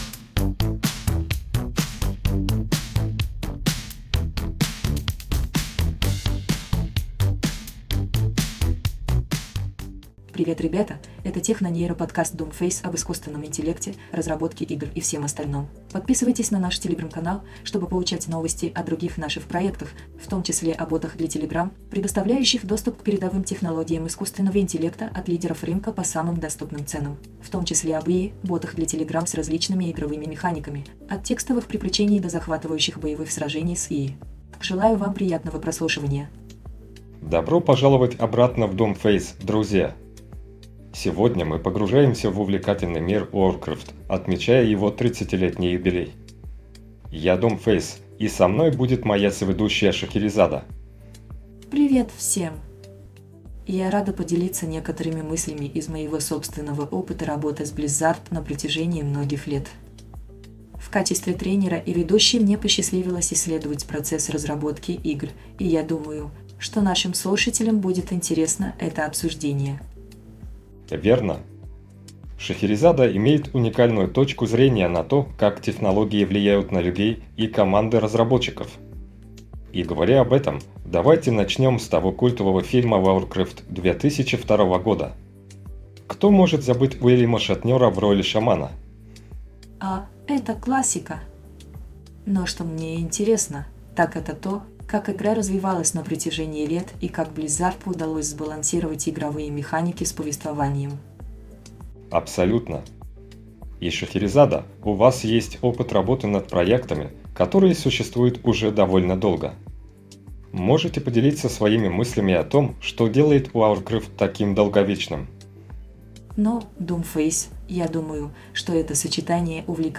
В заключение ведущие говорят о будущем игр и искусственного интеллекта, подчеркивая важность инноваций и уважения к времени игроков. https